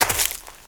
STEPS Leaves, Walk 01.wav